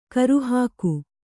♪ karuhāku